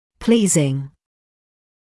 [‘pliːzɪŋ][‘плиːзин]приятный, доставляющий удовольствие; нравящийся, привлекательный; инговая форма от to please